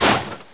mission_sound_droidland.wav